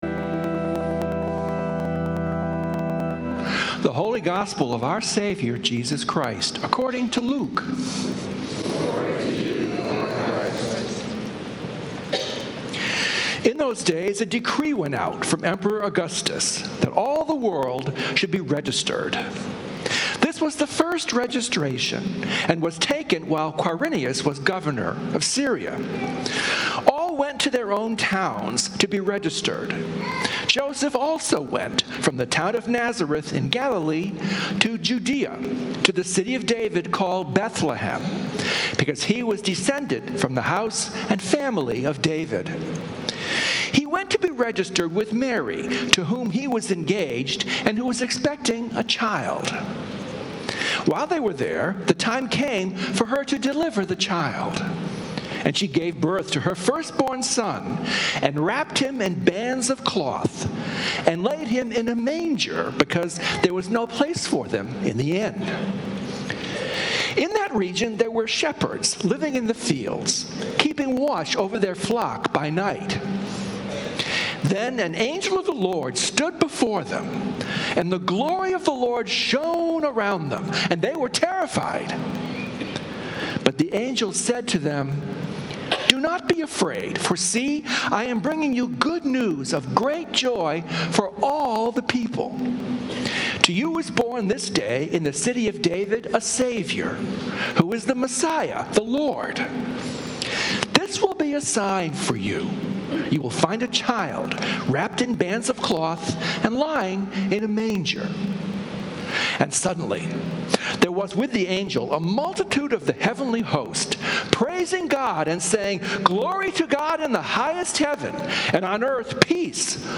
Christmas Eve Sermon